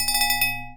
chime_bell_03.wav